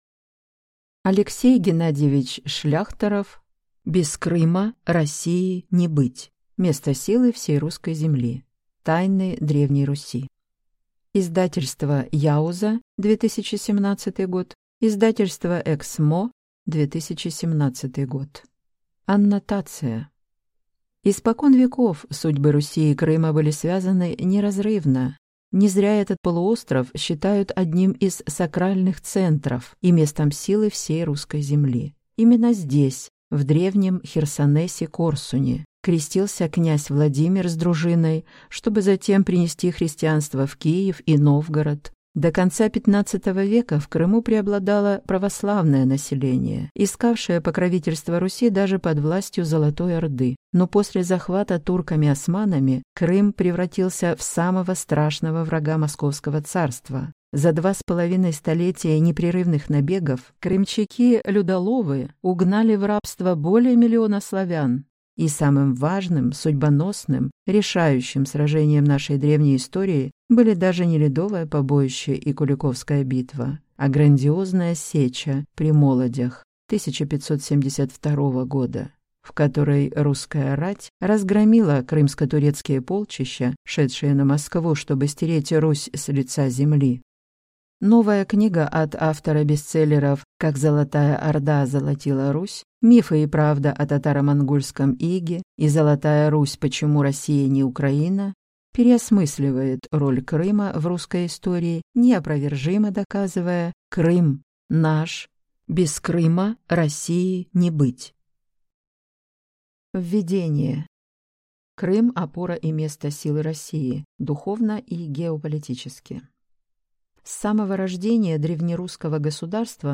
Аудиокнига Без Крыма России не быть! «Место силы» всей Русской Земли | Библиотека аудиокниг